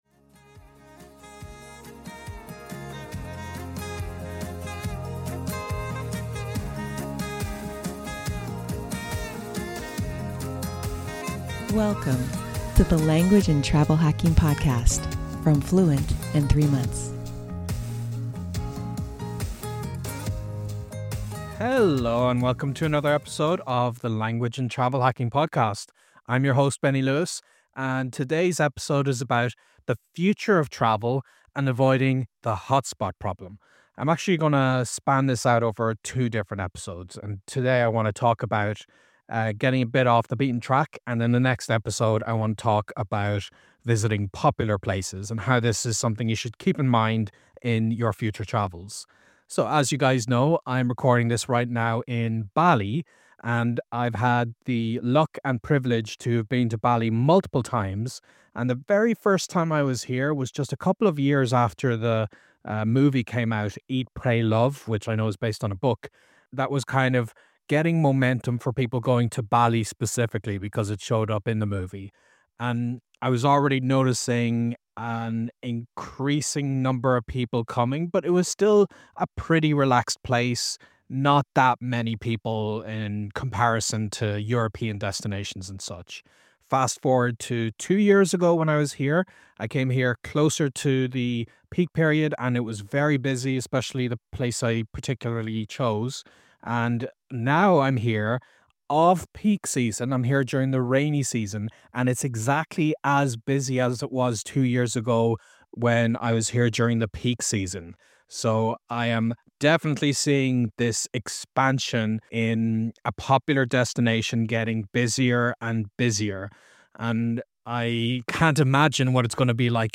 Recorded from bustling Bali, I’ll share my firsthand experience of how even off-peak seasons feel like peak season—and why this trend is happening globally.